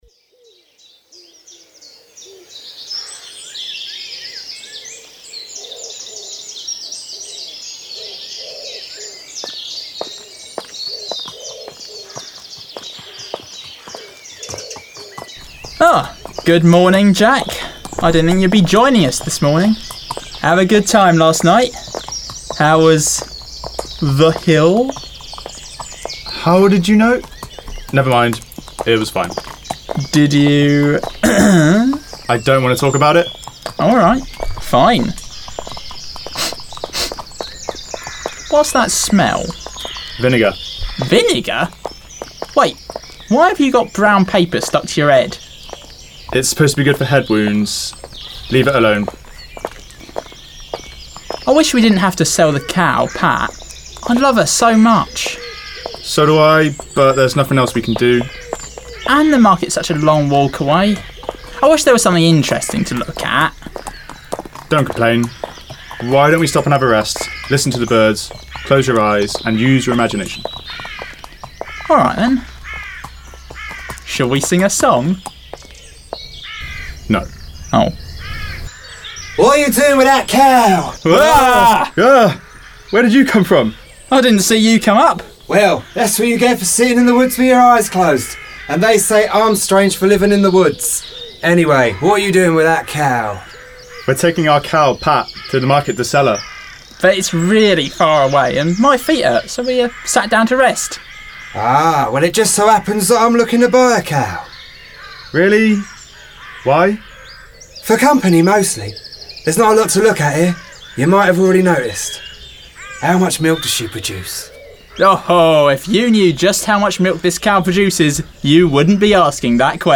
Then listen to our pantomime!